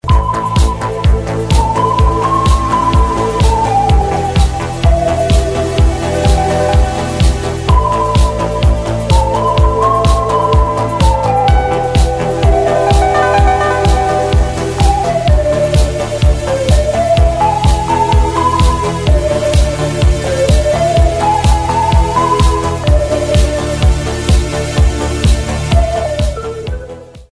80, 90, Рэтро